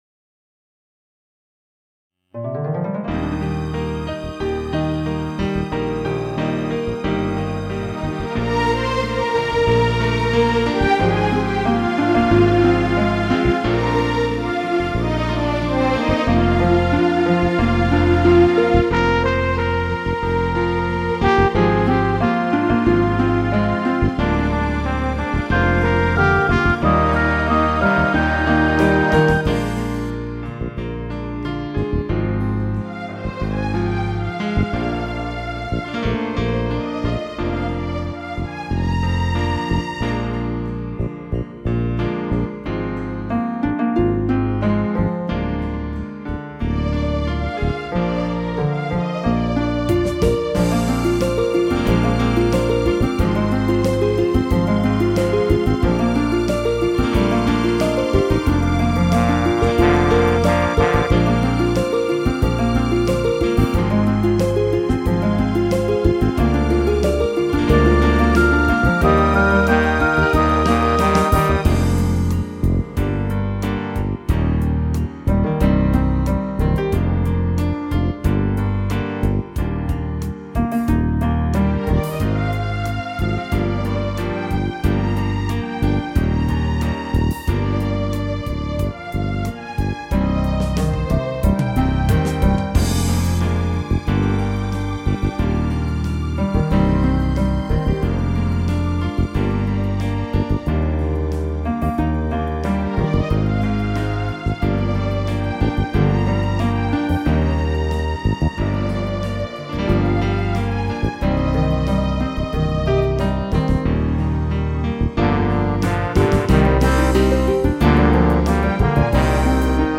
bE调
这歌以流行风格写成，ABA1三段结构（B段是小调，A1是A段的升调变奏）。